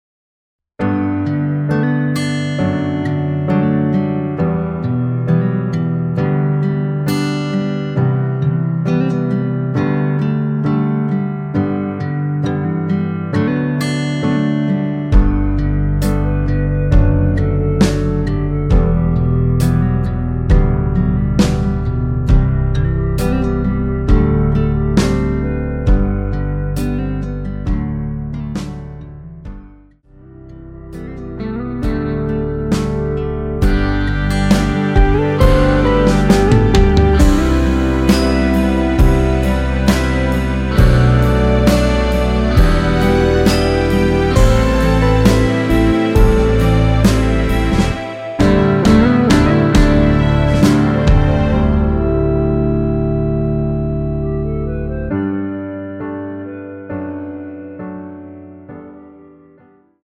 원키에서(-1)내린 멜로디 포함된 1절후 후렴(2절삭제)으로 진행되는 MR 입니다.(미리듣기및 가사 참조)
앞부분30초, 뒷부분30초씩 편집해서 올려 드리고 있습니다.
중간에 음이 끈어지고 다시 나오는 이유는